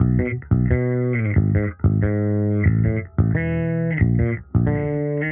Index of /musicradar/sampled-funk-soul-samples/90bpm/Bass
SSF_JBassProc1_90B.wav